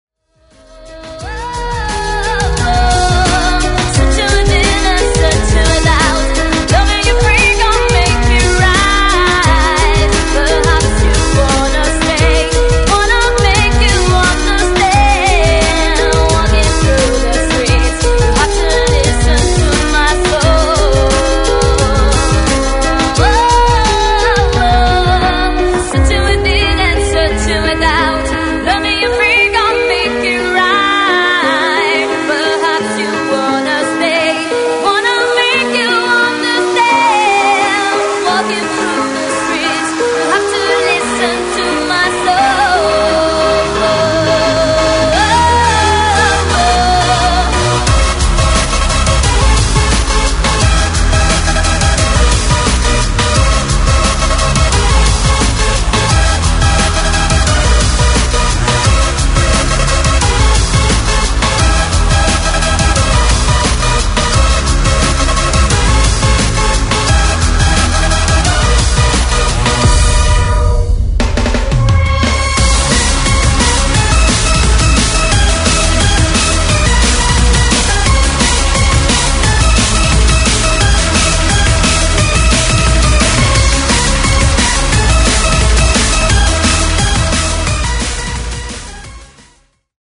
Freeform/Hardcore/Happy Hardcore